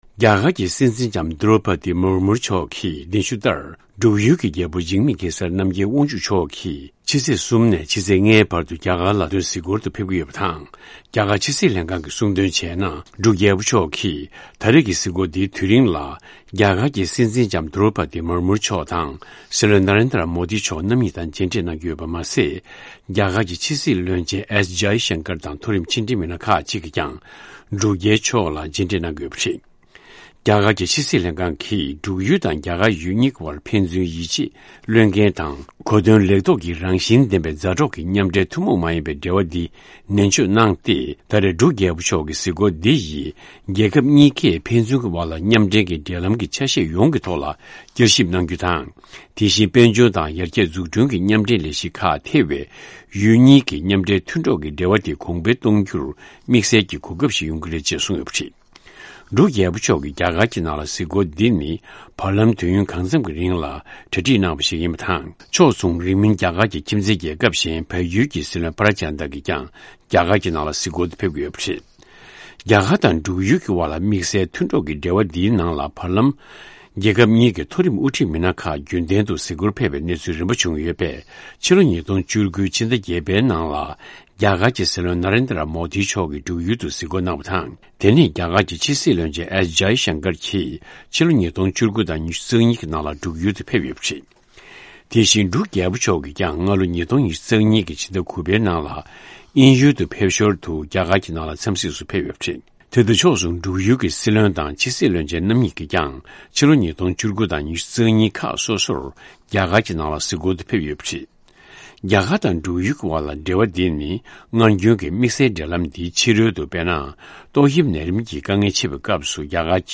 གནས་ཚུལ་ཕྱོགས་བསྡུས་ཞུས་གནང་བ་གཤམ་གསལ།